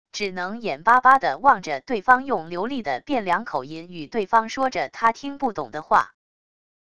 只能眼巴巴的望着对方用流利的汴梁口音与对方说着他听不懂的话wav音频生成系统WAV Audio Player